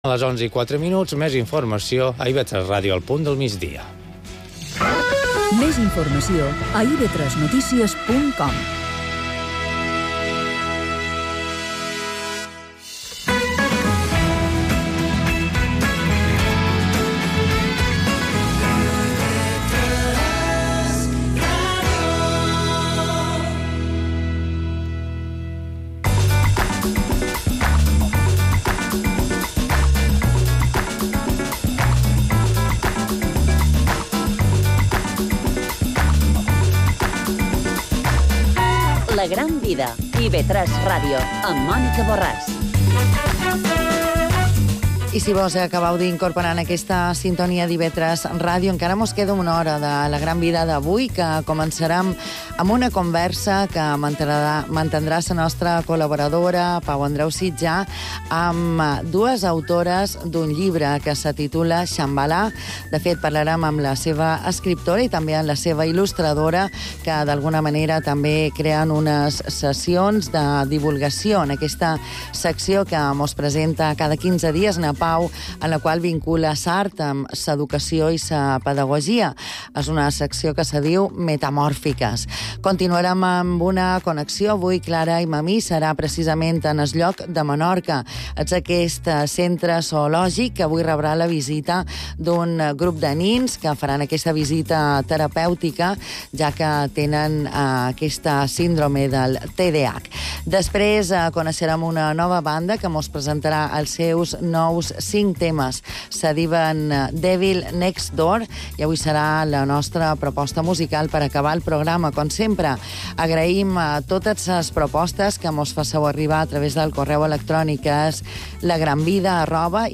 Entrevista en Metamòrfiques, IB3 radio.
Entrevista sobre Shambala Escuela de Dioses /Educación y Arte/ en IB3/a la carta